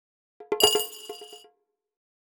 Positive Vibes Coin.wav